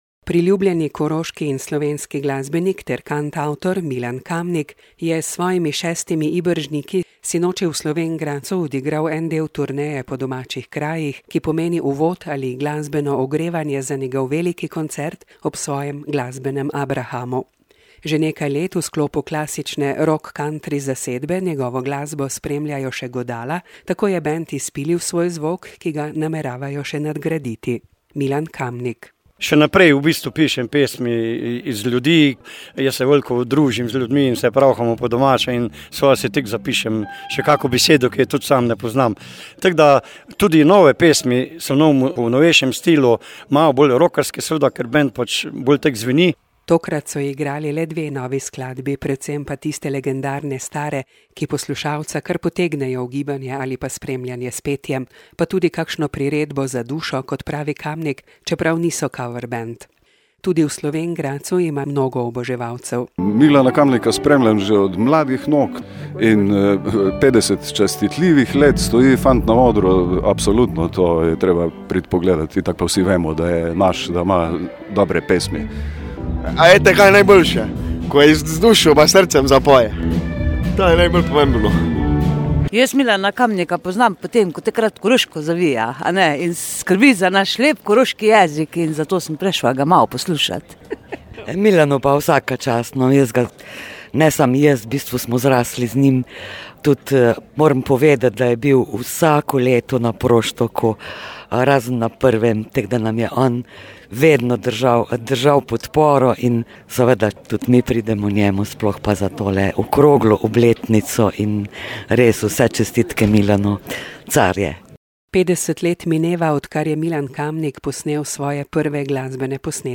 Lokalne novice | Koroški radio - ritem Koroške
Že nekaj let v sklopu klasične – rock/country zasedbe njegovo glasbo spremljajo še godala, tako je bend izpilili svoj zvok, ki ga nameravajo še nadgraditi.